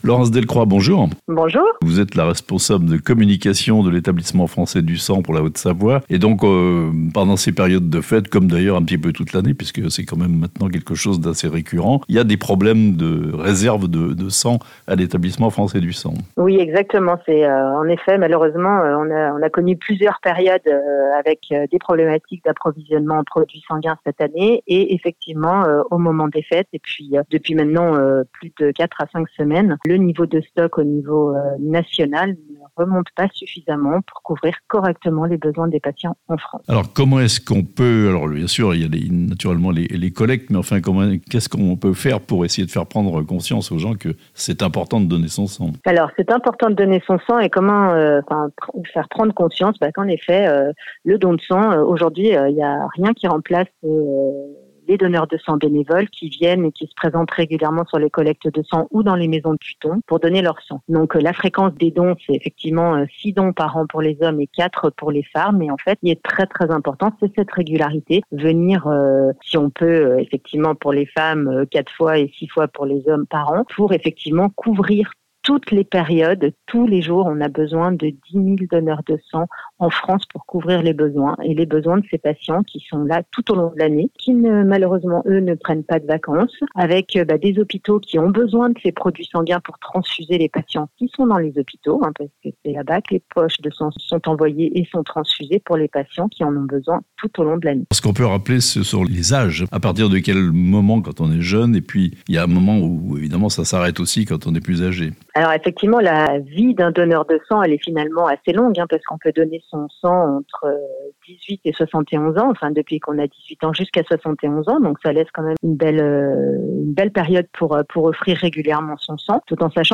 SOS dons de sang en Haute-Savoie (interview)